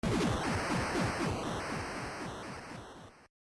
destroyed.ogg